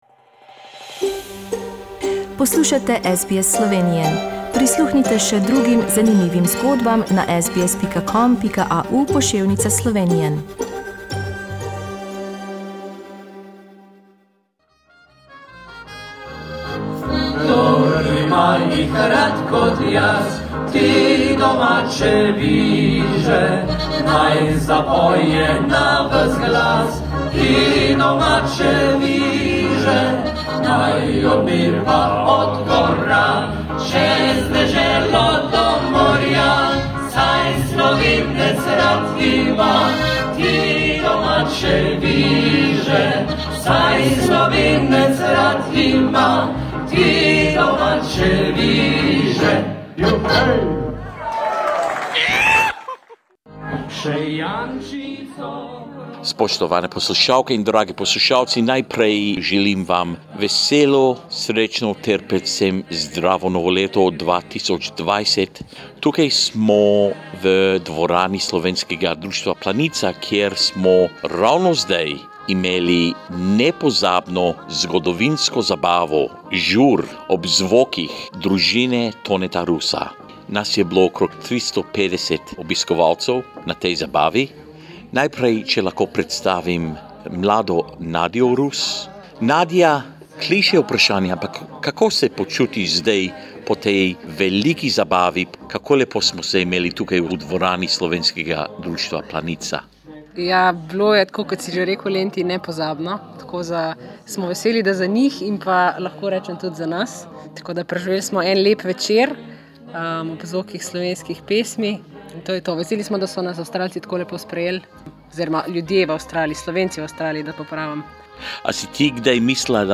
Family Rus from Slovenia performed for the Australian Slovenian community in Melbourne on New Year's Eve at Slovenian Association Planica and made it an unforgettable night for many. We spoke with the band members in the early hours of the New Year and also bring you some of their songs.